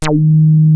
WEST HARD#D3.wav